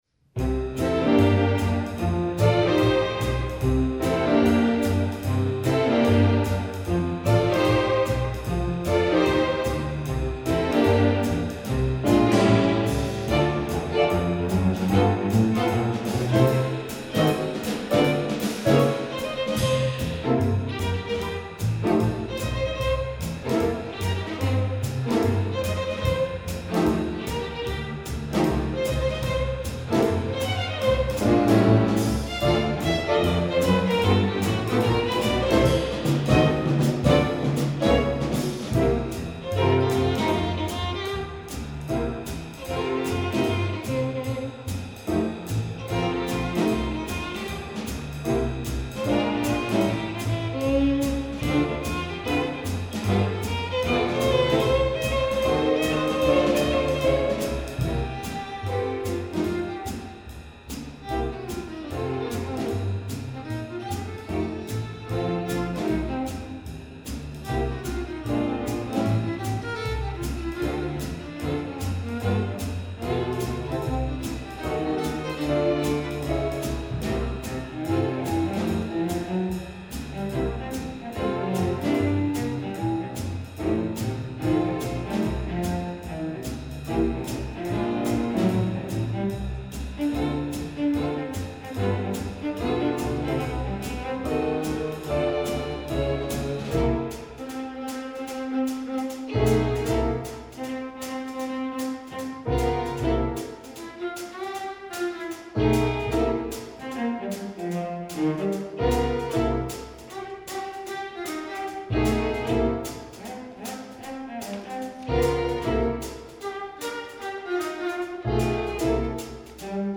Drums part:
Piano accompaniment part: